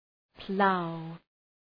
Προφορά
{plaʋ}